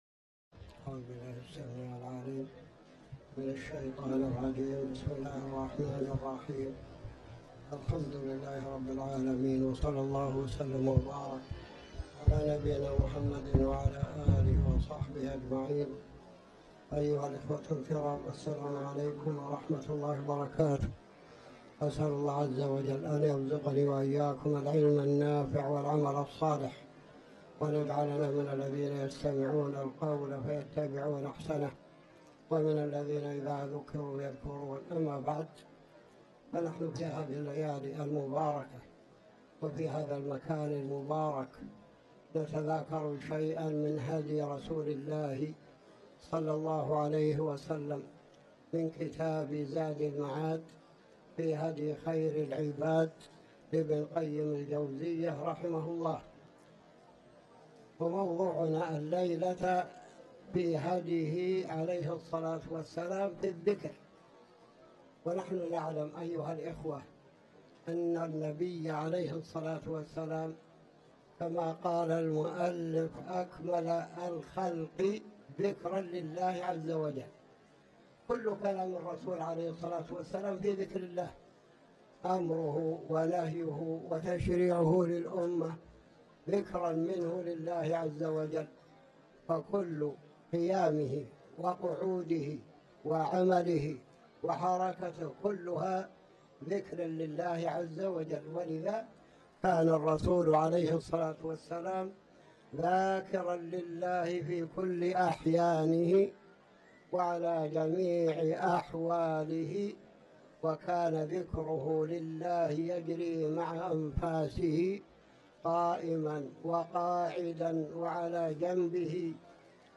تاريخ النشر ٢٧ جمادى الأولى ١٤٤٠ هـ المكان: المسجد الحرام الشيخ